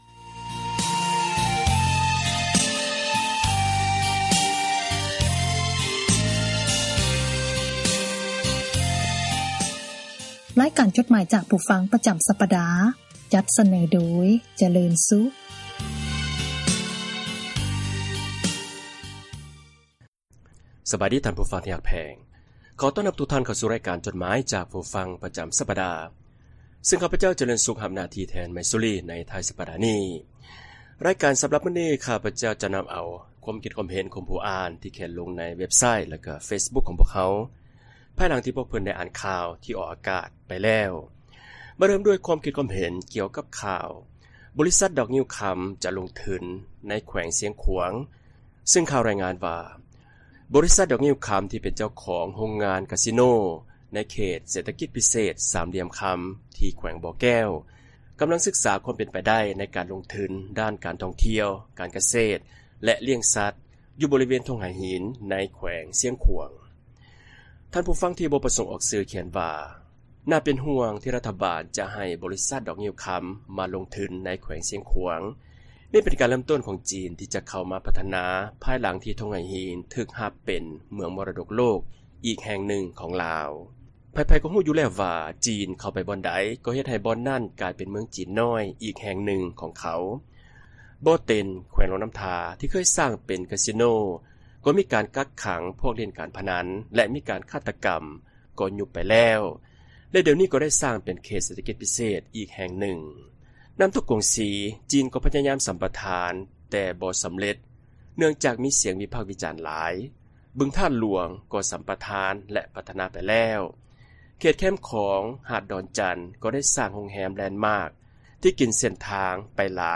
ອ່ານຈົດໝາຍ